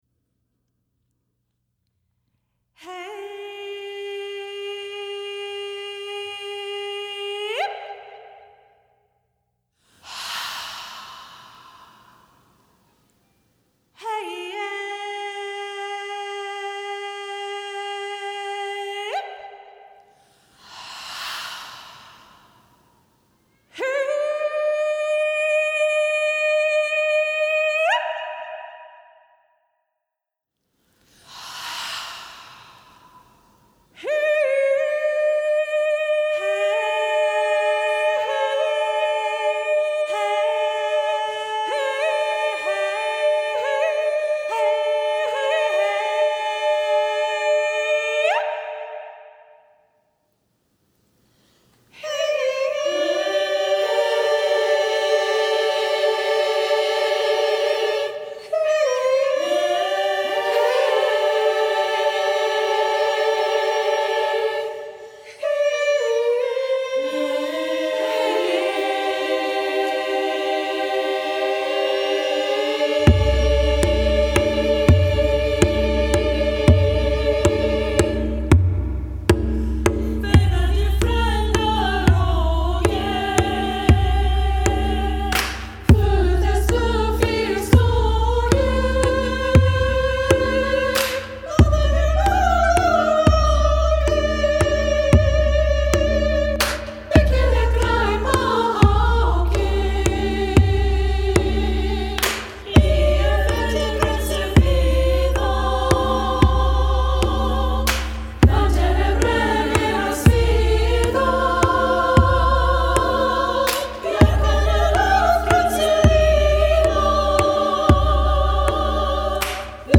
for SSA choir and percussion